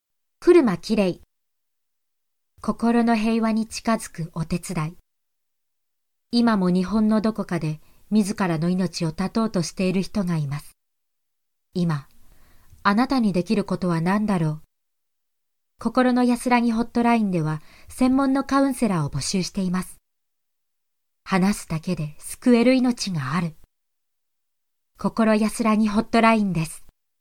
ボイス